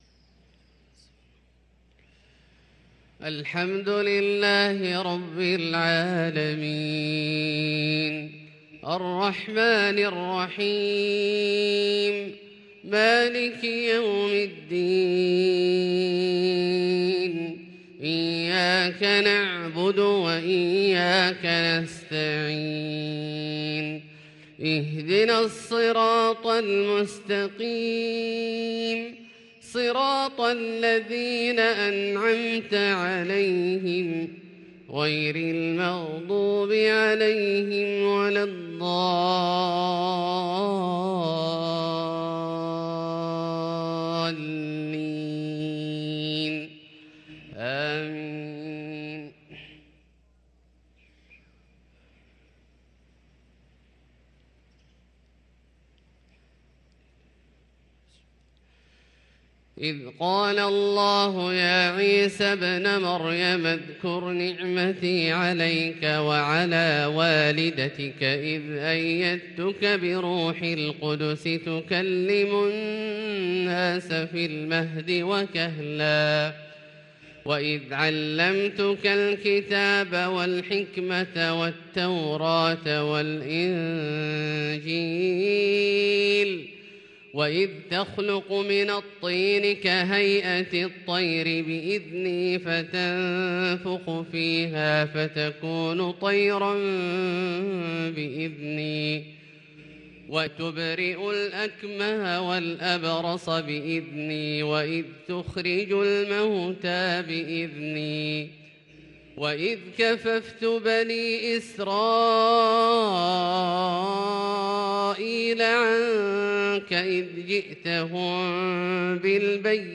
صلاة الفجر للقارئ عبدالله الجهني 5 صفر 1444 هـ
تِلَاوَات الْحَرَمَيْن .